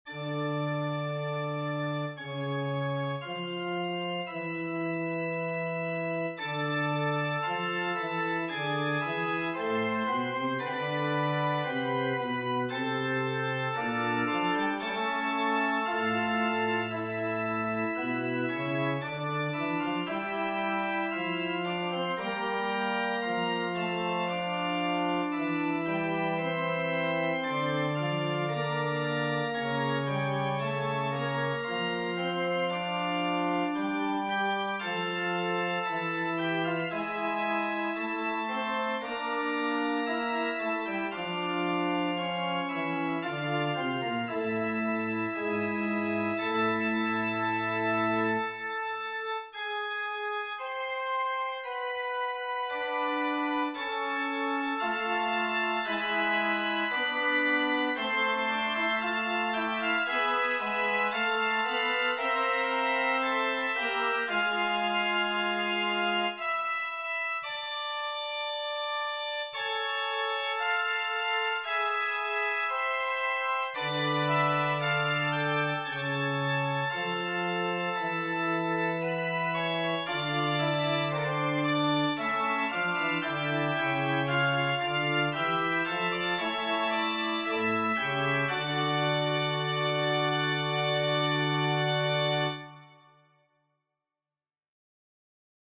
Hi, I wanted some feedback on a basic Fugue I composed.